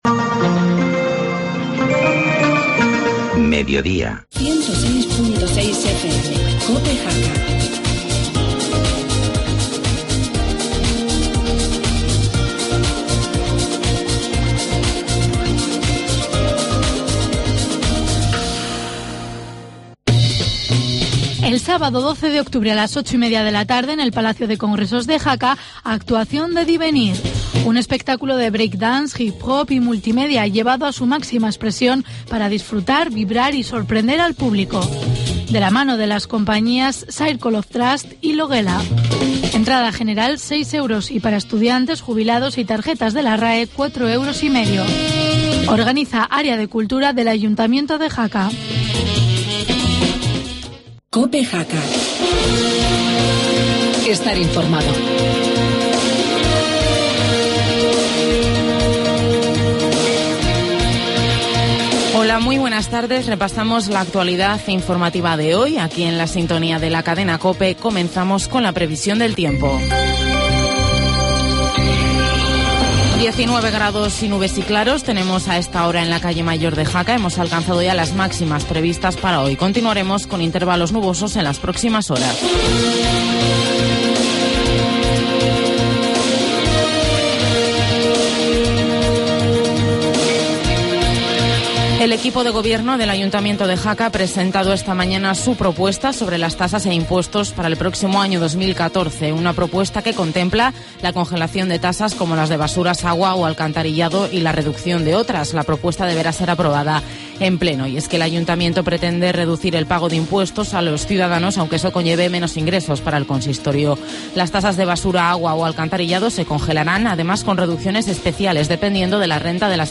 Informativo mediodía, jueves 10 de octubre